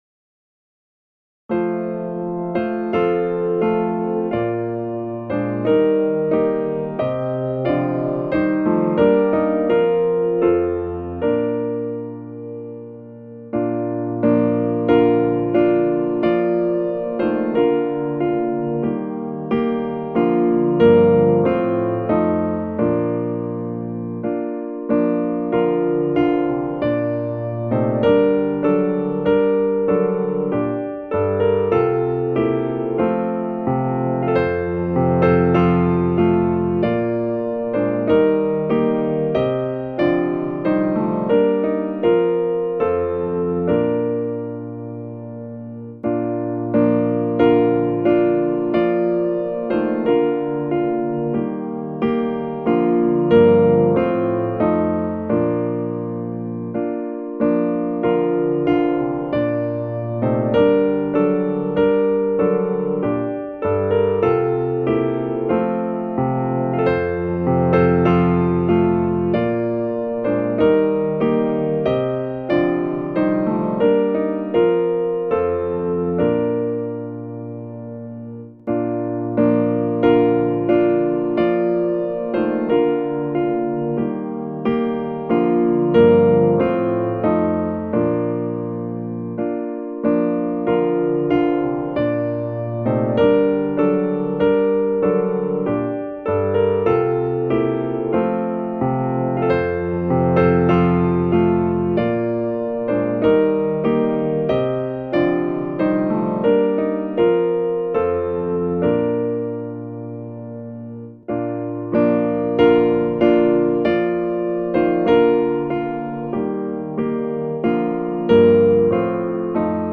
a majestic melody